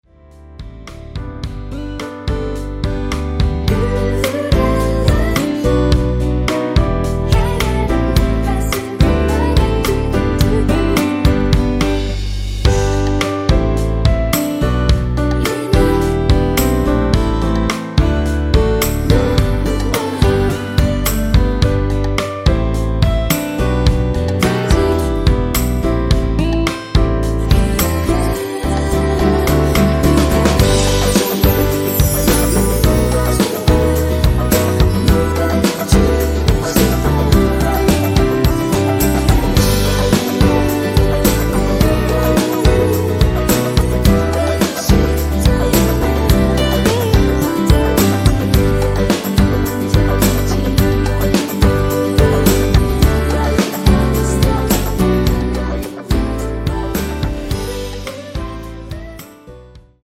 원키 코러스 포함된 MR입니다.(미리듣기 확인)
앞부분30초, 뒷부분30초씩 편집해서 올려 드리고 있습니다.
중간에 음이 끈어지고 다시 나오는 이유는